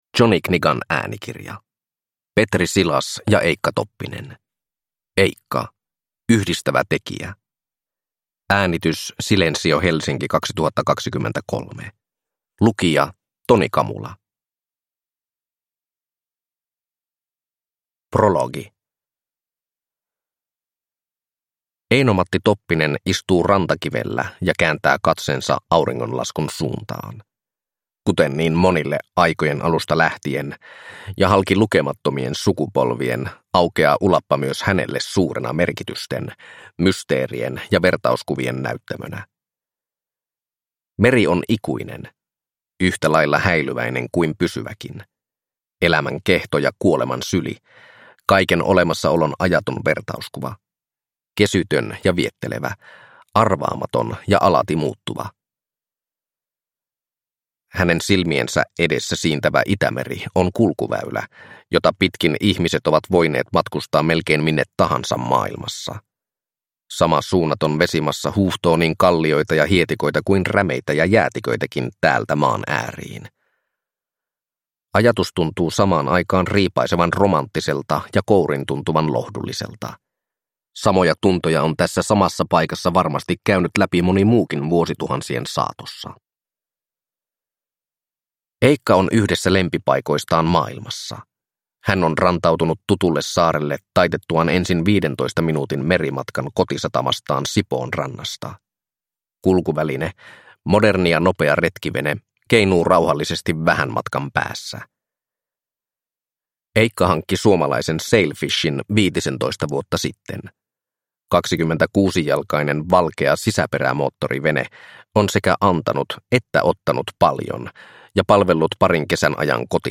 Eicca – Yhdistävä tekijä – Ljudbok